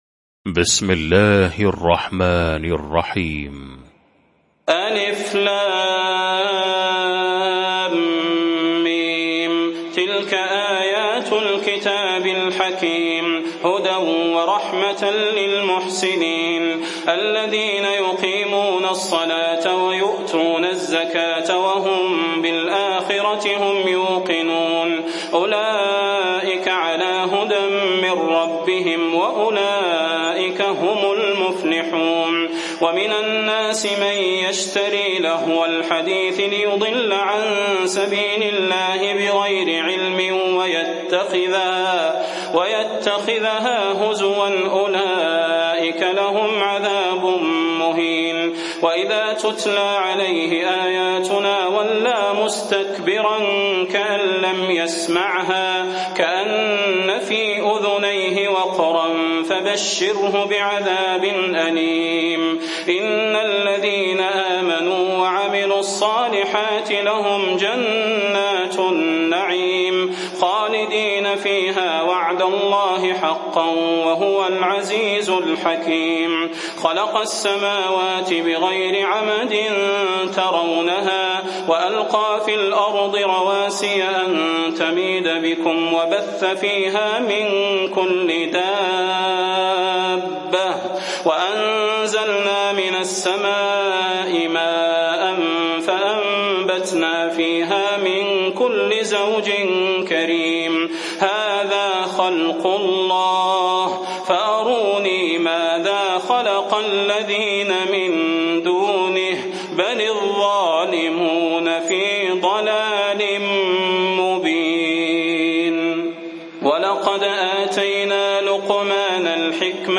المكان: المسجد النبوي الشيخ: فضيلة الشيخ د. صلاح بن محمد البدير فضيلة الشيخ د. صلاح بن محمد البدير لقمان